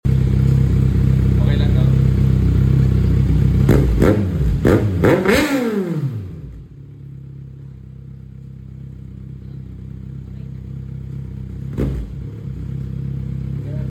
Quick Soundcheck Z1000 🔥